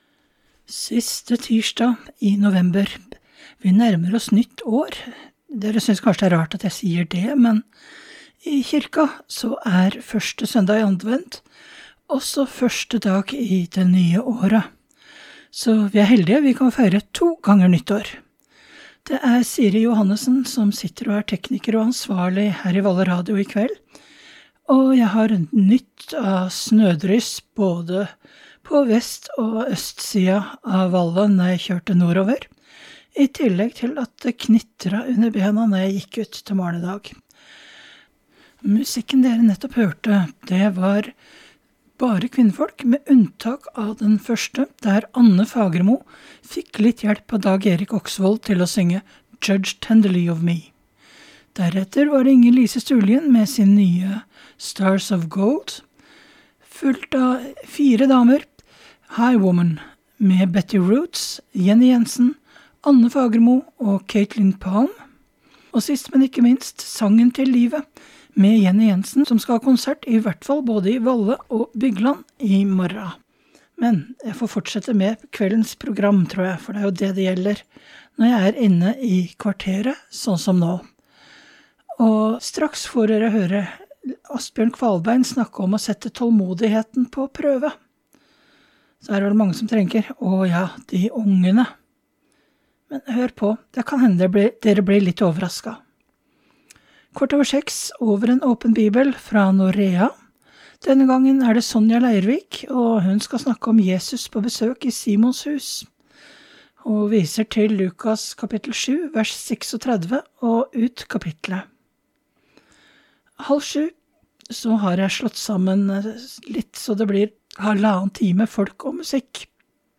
Denne kvelden var dessverre lydkvaliteten på FM og internett dårlig, noe som førte til at onsdagssendingen bare ble delt via Setesdalswiki.